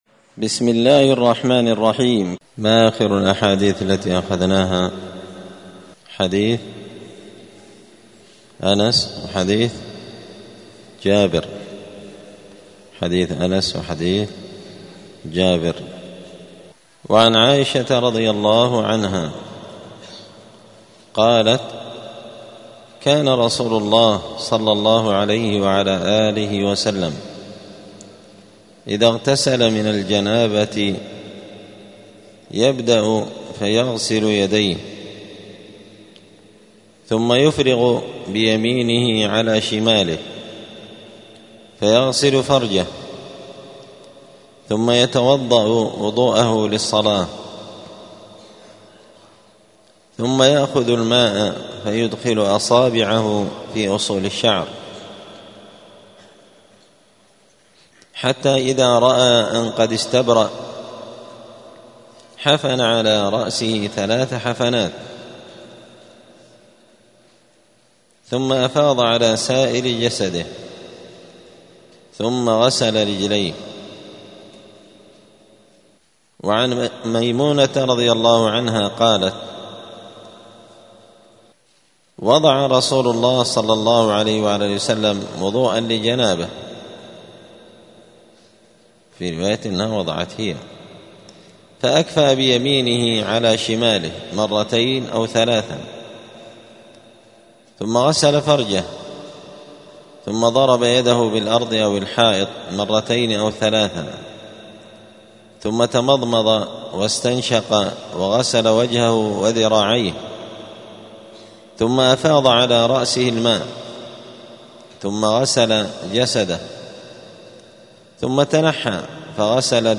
دار الحديث السلفية بمسجد الفرقان قشن المهرة اليمن
*الدرس الثمانون [80] {باب صفة الغسل صفة غسل الجنابة}*